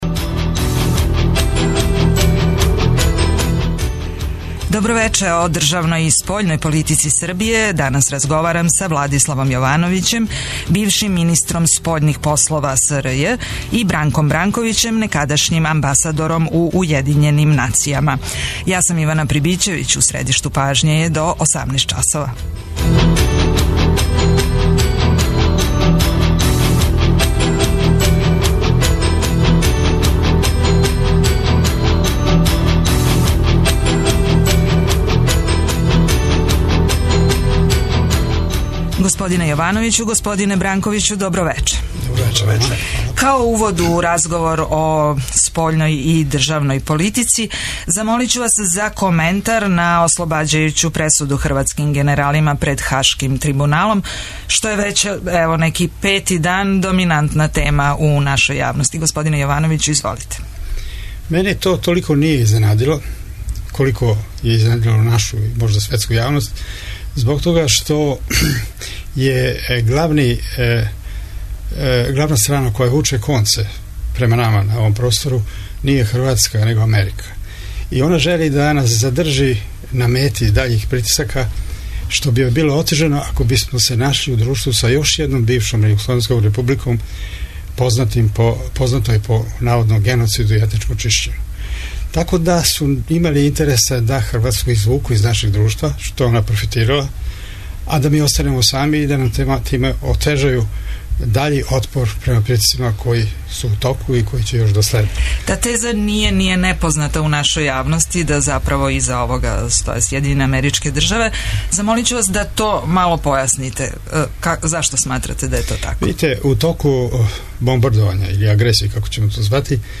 Шта ће конкретно значити спровођење споразума о интегрисаном управљању прелазима на Косову и Метохији? О српској државној и спољној политици разговарамо са Владиславом Јовановићем, некадашњим министром спољних послова СРЈ и Бранком Бранковићем, бившим амбасадором у Уједињеним нацијама.
преузми : 25.56 MB У средишту пажње Autor: Редакција магазинског програма Свакога радног дана од 17 часова емисија "У средишту пажње" доноси интервју са нашим најбољим аналитичарима и коментаторима, политичарима и експертима, друштвеним иноваторима и другим познатим личностима, или личностима које ће убрзо постати познате.